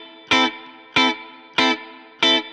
DD_StratChop_95-Amin.wav